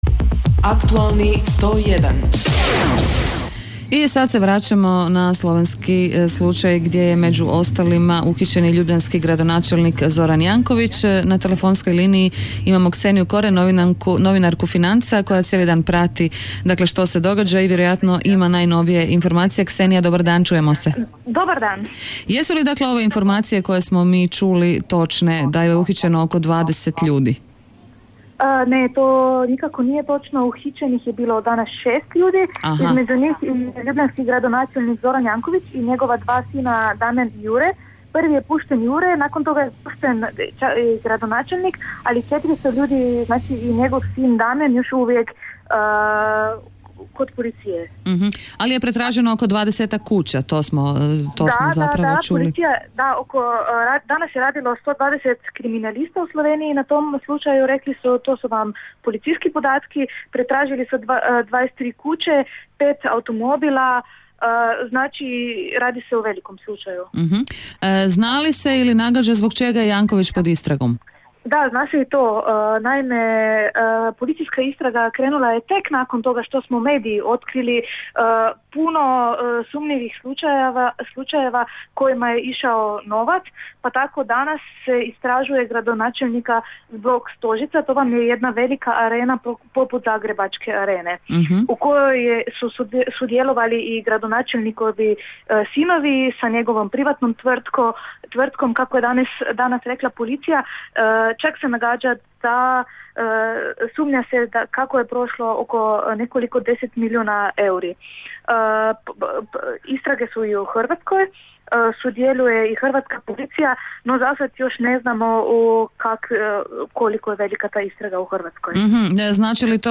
U akciji slovenske policije priveden gradonačelnik Ljubljane Zoran Janković. Javljanje